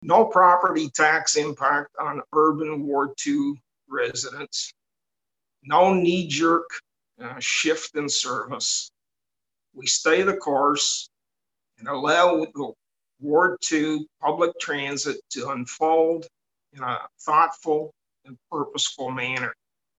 At a city council meeting Monday Councillor Bill Sandison says the regular bus and mobility bus service now available on a trial basis until the end of September could be able to continue until the end of March next year.